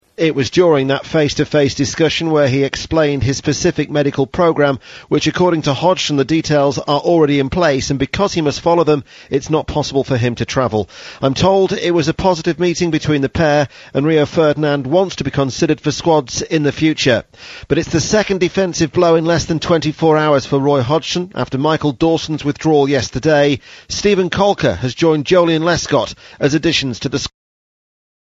【英音模仿秀】英格兰急招热刺新星入替 听力文件下载—在线英语听力室